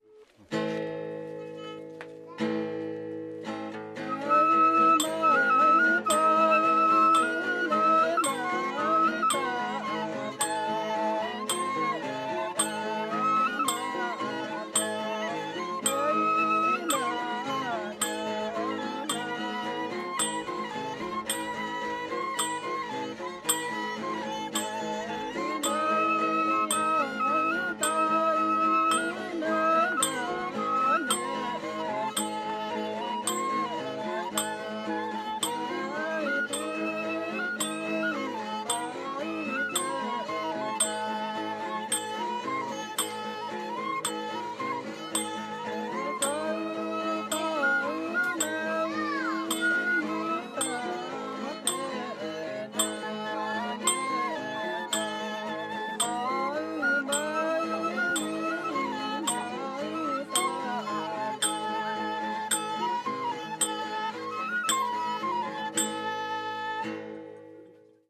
dance music the ensemble has guitar, flute, fiddle and percussion accompanying a singer; the song relates that the mountain spirits were not happy with the food the villagers offered so took their daughters too 1MB
The music of the Padaung I have recorded has mostly been pentatonic and in the form of gentle very repetitive songs, though traditional songs veer into freer scales like Karen solo singing.
Track 18 Padong ensemble.mp3